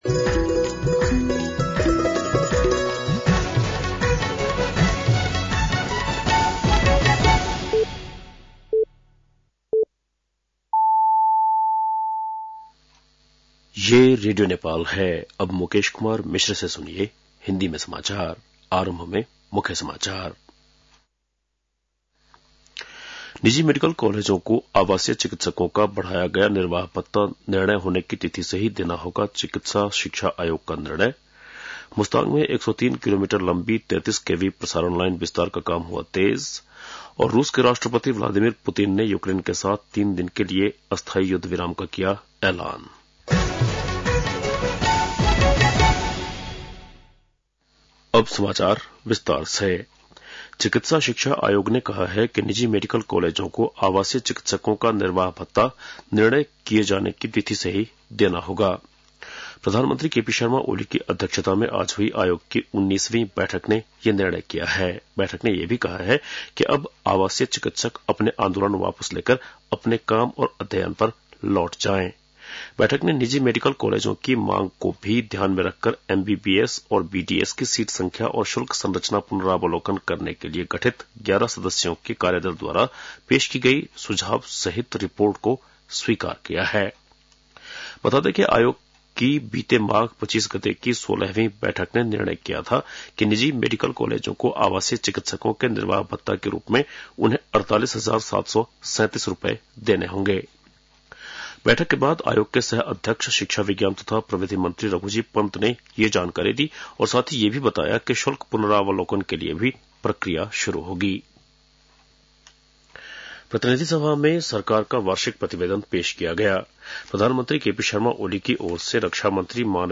बेलुकी १० बजेको हिन्दी समाचार : १५ वैशाख , २०८२